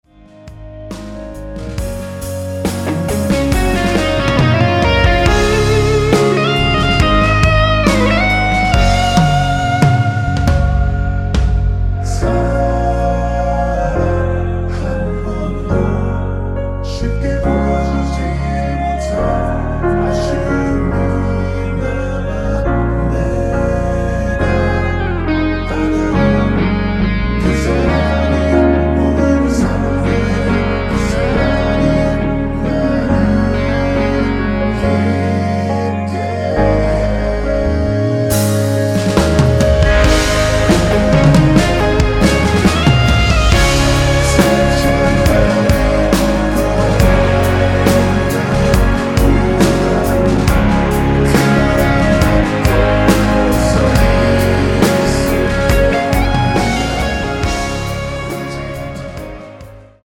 원키에서(-3)내린 멜로디와 코러스 포함된 MR입니다.(미리듣기 확인)
Db
앞부분30초, 뒷부분30초씩 편집해서 올려 드리고 있습니다.
중간에 음이 끈어지고 다시 나오는 이유는